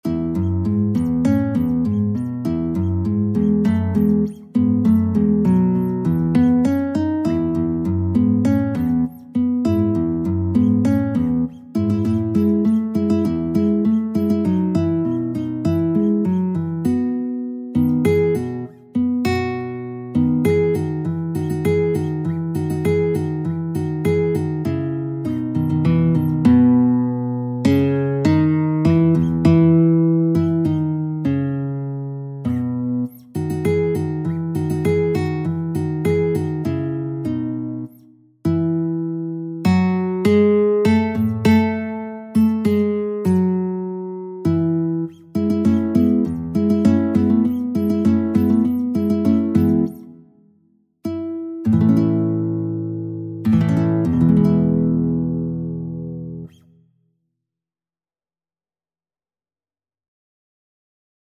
E major (Sounding Pitch) (View more E major Music for Guitar )
4/4 (View more 4/4 Music)
Allegro (View more music marked Allegro)
Guitar  (View more Intermediate Guitar Music)
Classical (View more Classical Guitar Music)
messiah_hallelujah_GUIT.mp3